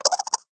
alien.ogg